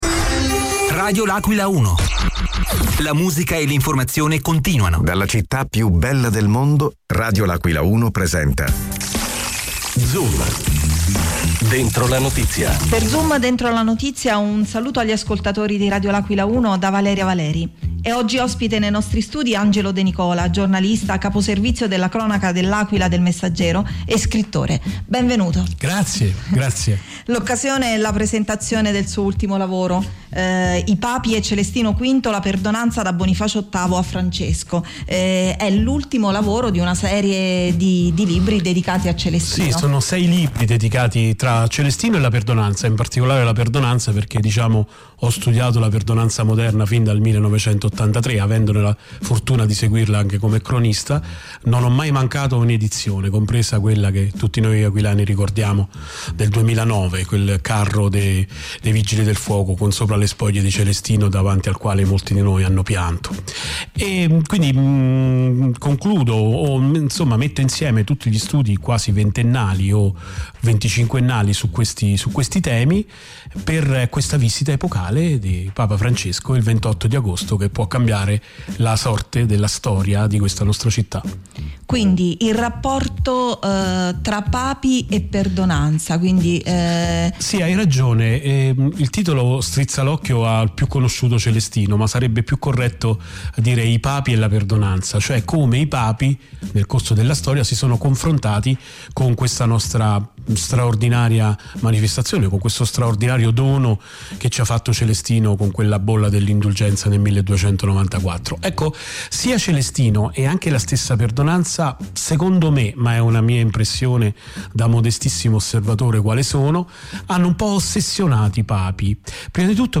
L’AQUILA – Negli studi di Radio L’Aquila 1